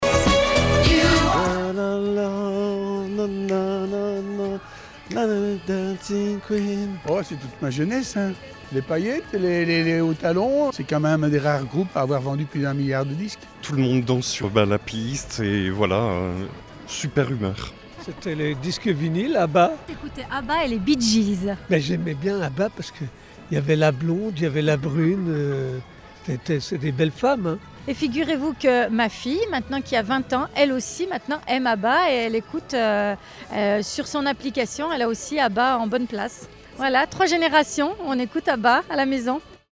Même chose dans les rues de Nice où vous êtes toujours aussi fans Télécharger le podcast Partager :